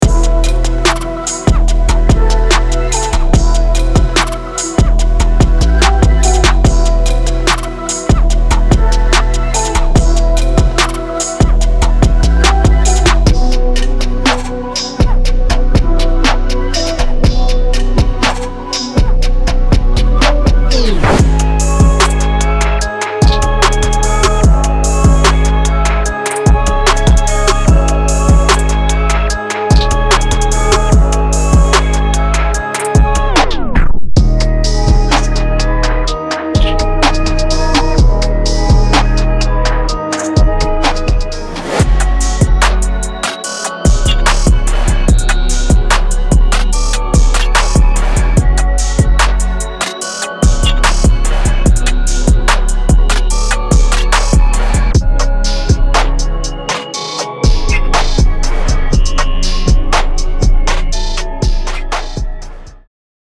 Hip-Hop / R&B Trap
• 08 Full Drum Loops
• 47 Melody Loops